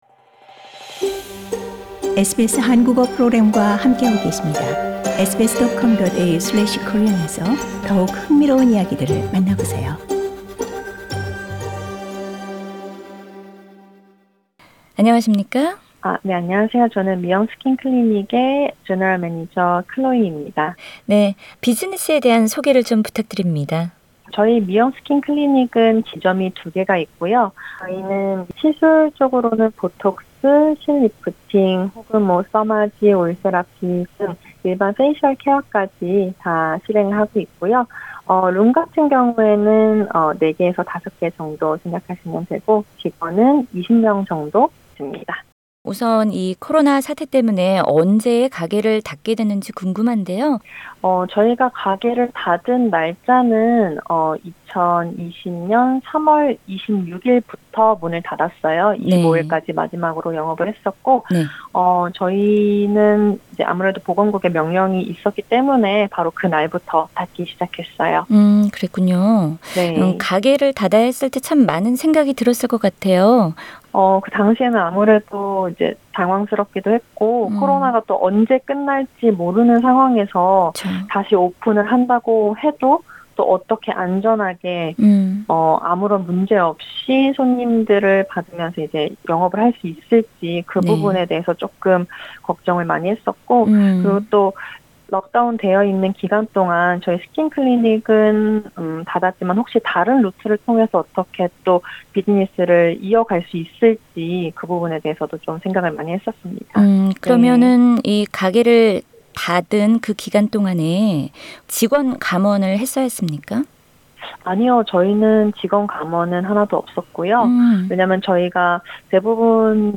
[The Full interview is available on the podcast above] Source: SBS The NSW Government launched its online COVID Safe Check for businesses in June.